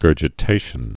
(gûrjĭ-tāshən)